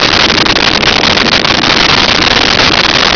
Sfx Surface Woodbridg Loop
sfx_surface_woodbridg_loop.wav